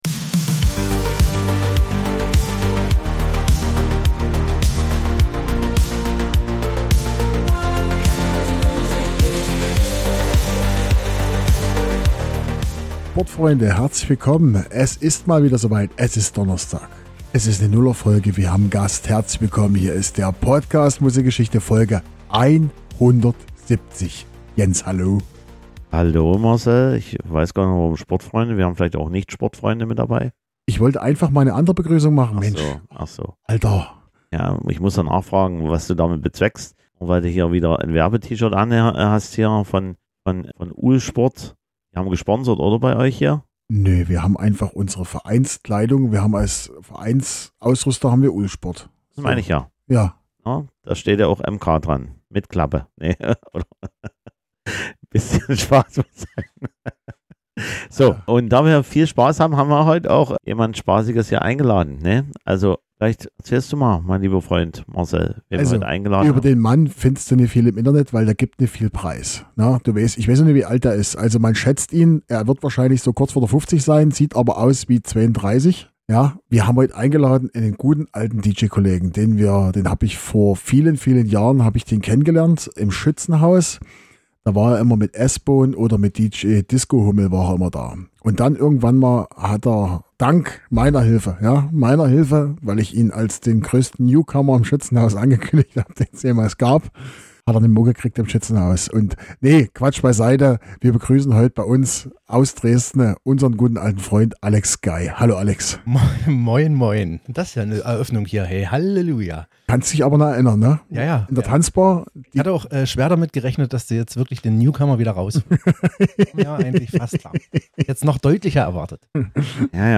im Kellerstudio besucht